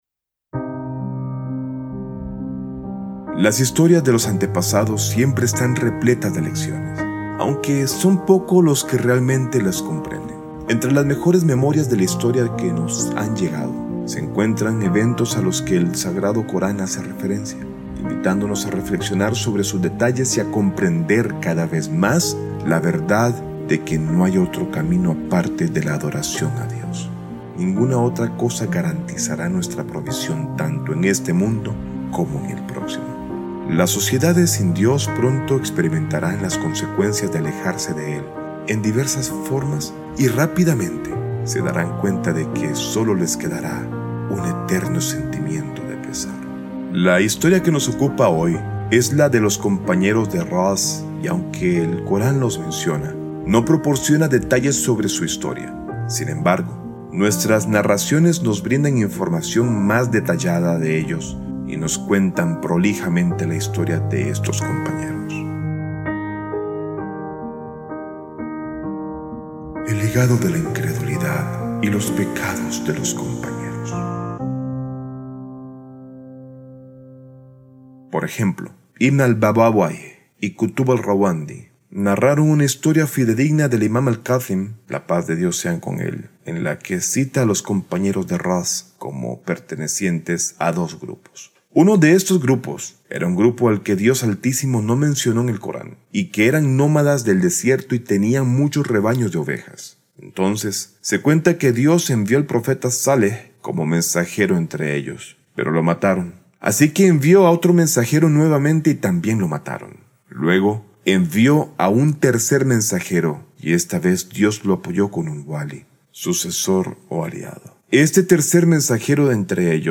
Descripción Este episodio presenta una narración profunda y aleccionadora basada en relatos coránicos y transmisiones auténticas de los Imames, que revelan el destino de comunidades que rechazaron reiteradamente la verdad divina. A través de la historia de los Compañeros de Rass, se muestra cómo la incredulidad, la idolatría y la corrupción moral llevan a la destrucción espiritual y social, mientras que la fe sincera y la obediencia a Dios son el único camino hacia la salvación en este mundo y en el Más Allá.